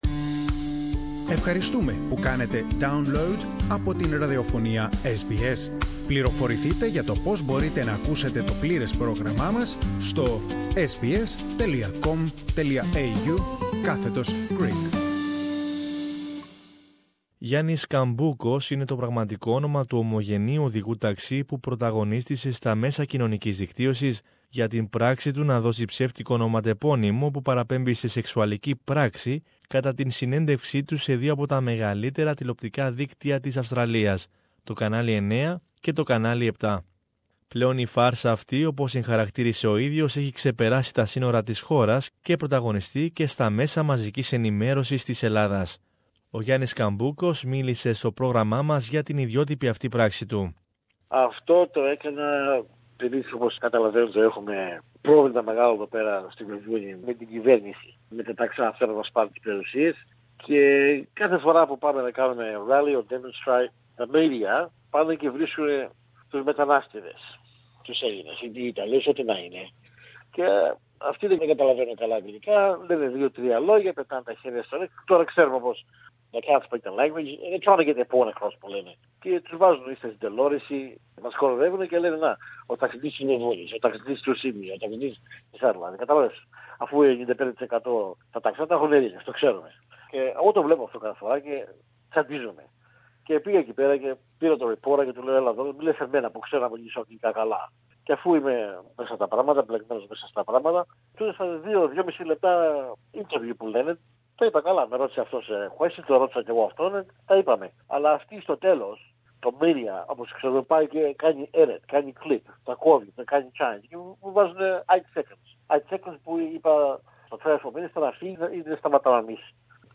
A Greek Australian taxi driver that used a fake rude name for an interview talks to SBS Radio